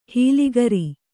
♪ hīli gari